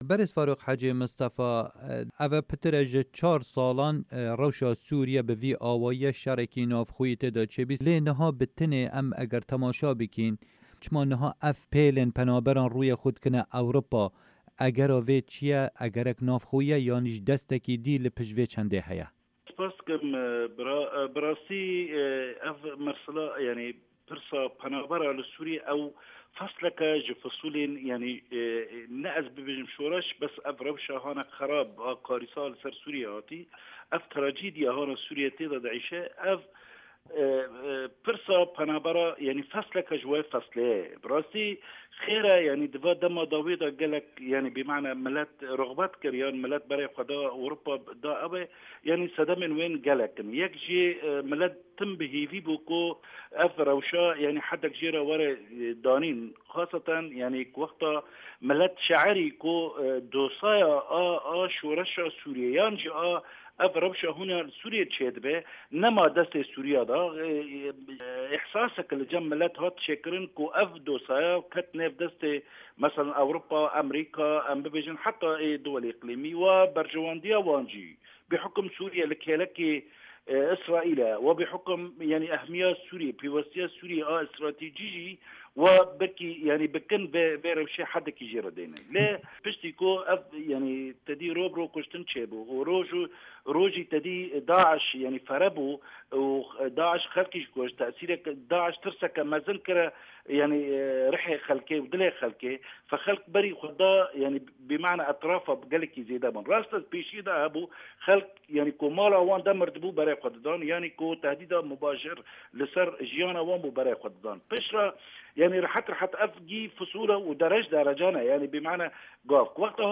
Di vê hevpeyvînê de rojnamevan